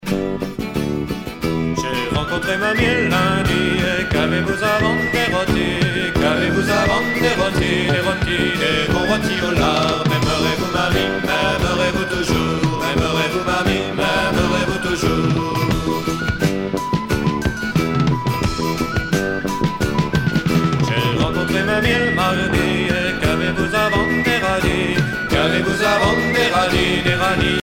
danse : hanter-dro
Genre énumérative
Pièce musicale éditée